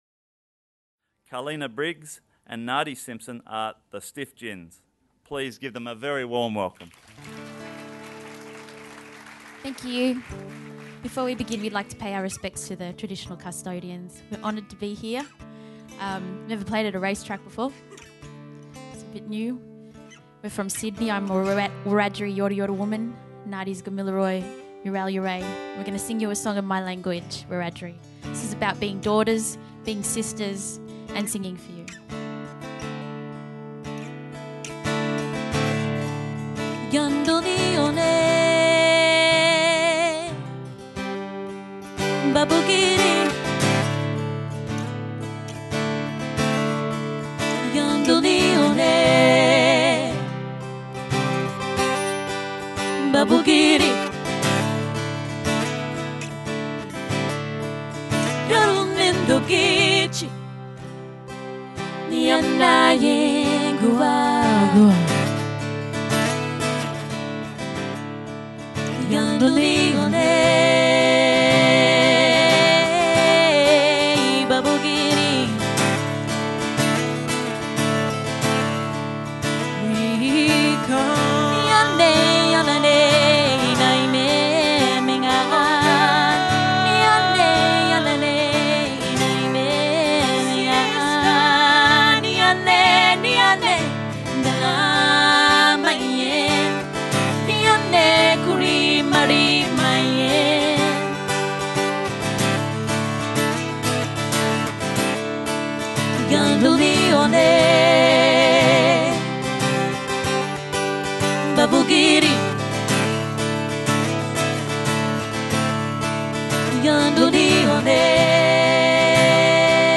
Celebrate fellowship and community with a spine-tingling performance by the Stiff Gins.